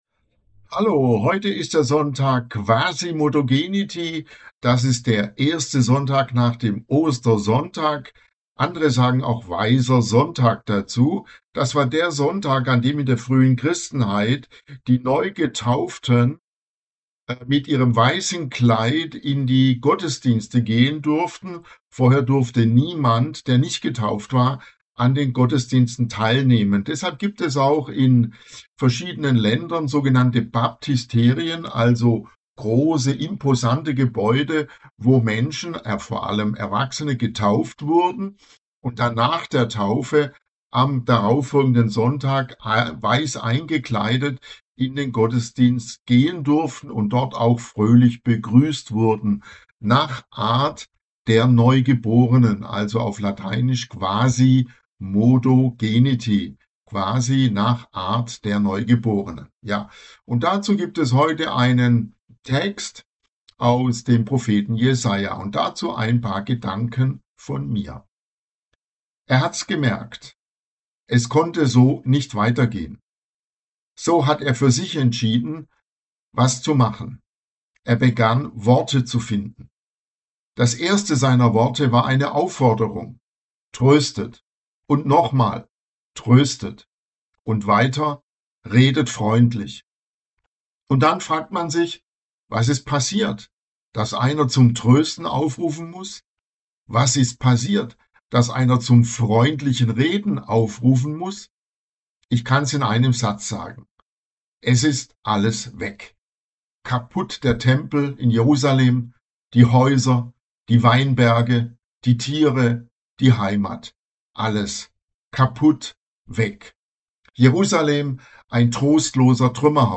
Einer hat´s gemerkt! Predigt zum Sonntag Quasimodogeniti